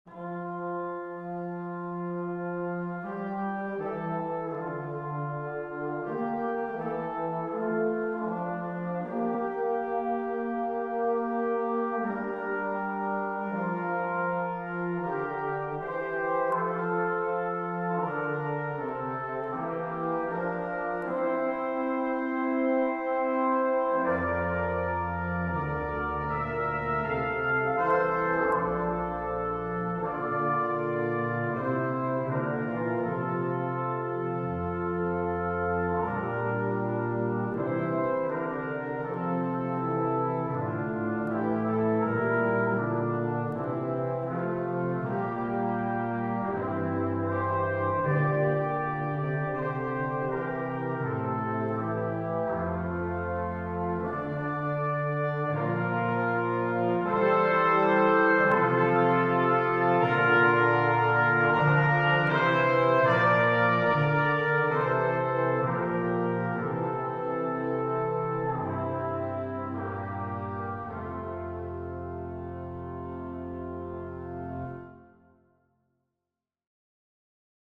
Benediction in Brass Trumpets(2), Trombone, Tuba
My choral Benediction arranged for a brass quartet.